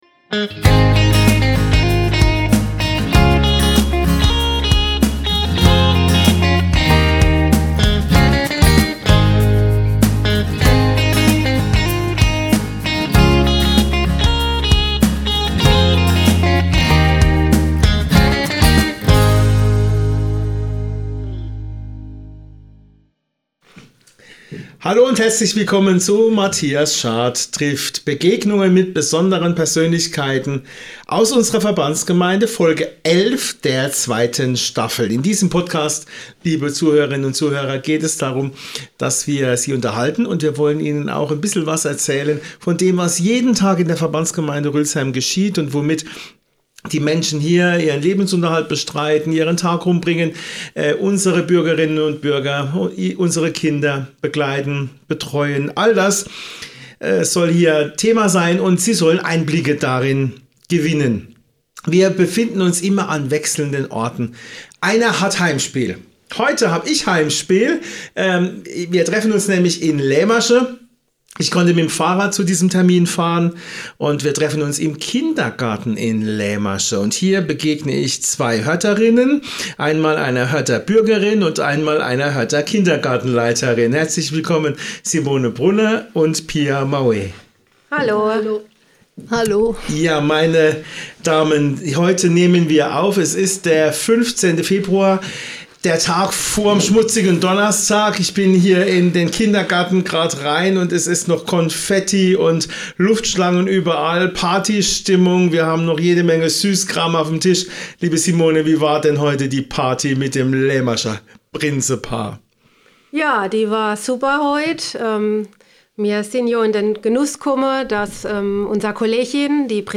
Im Kindergarten in Leimersheim unterhalten sie sich darüber, wie sich die Kinderbetreuung über die Jahre verändert hat, die Herausforderungen im KiTa-Alltag und den Umbau in Leimersheim beziehungsweise den Neubau in Hördt.